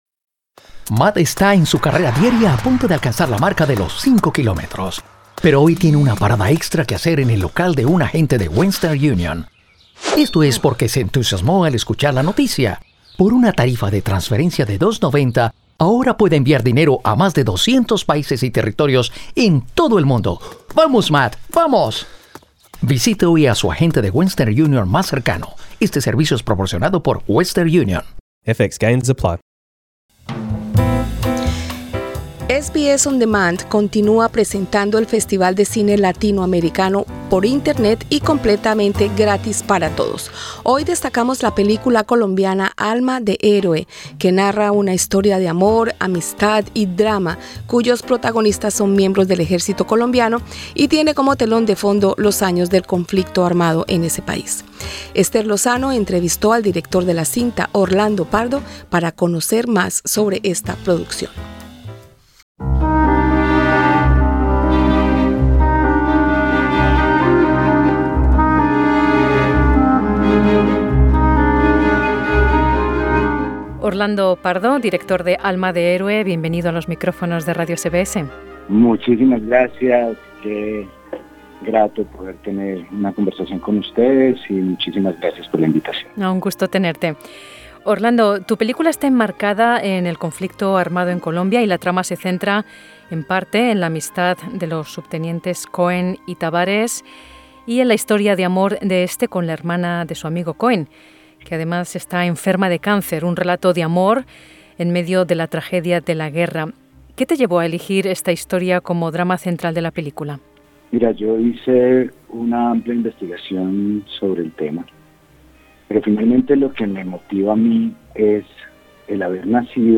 Escucha la entrevista completa presionando la imagen principal.